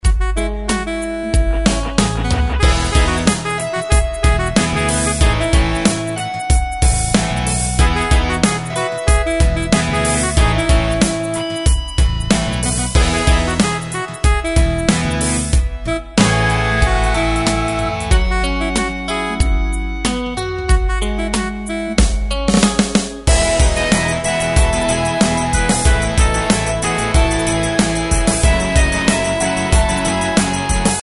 Tempo: 93 BPM.
MP3 with melody DEMO 30s (0.5 MB)zdarma